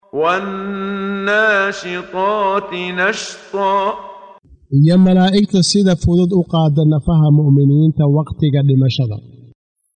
قراءة صوتية باللغة الصومالية لمعاني سورة النازعات مقسمة بالآيات، مصحوبة بتلاوة القارئ محمد صديق المنشاوي - رحمه الله -.